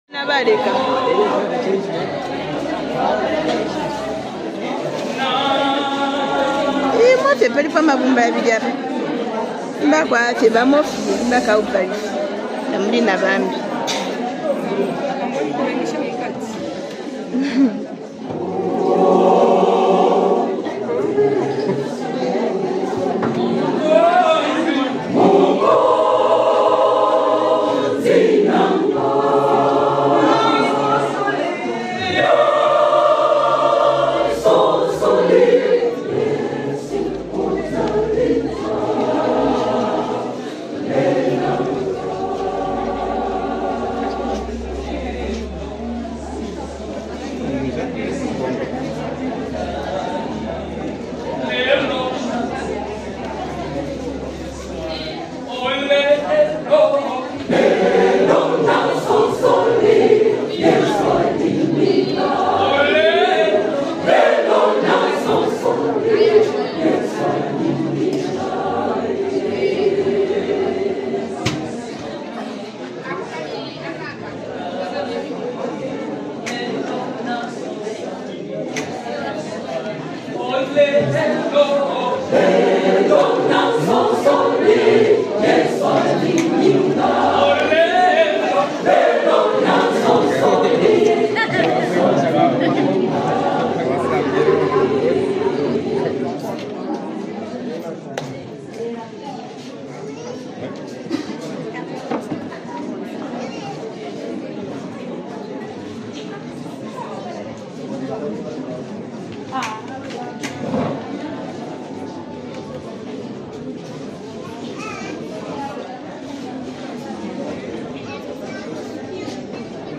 DEEP VILLAGE WORSHIP SOUND | 2025 ZAMBIA GOSPEL
TRADITIONAL WORSHIP SONG